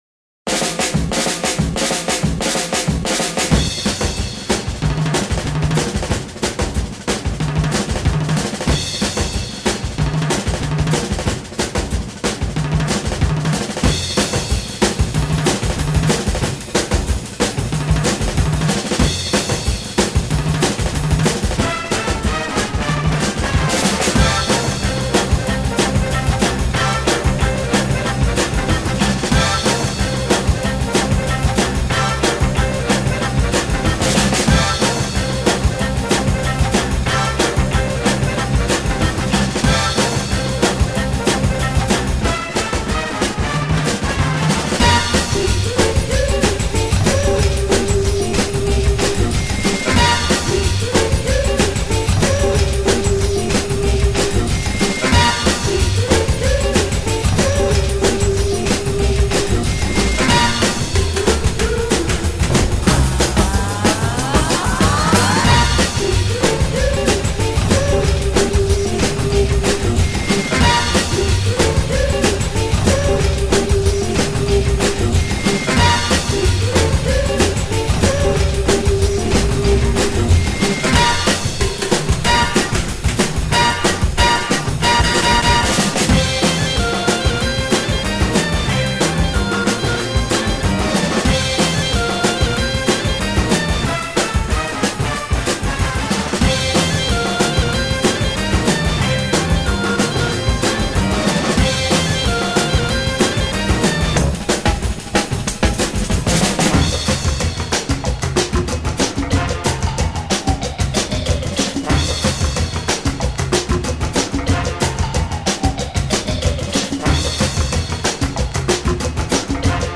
Uptempo electrokitsch surf rock /cartoon funk.